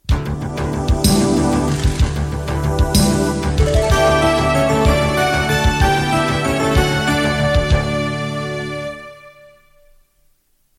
Sintonia dels serveis informatius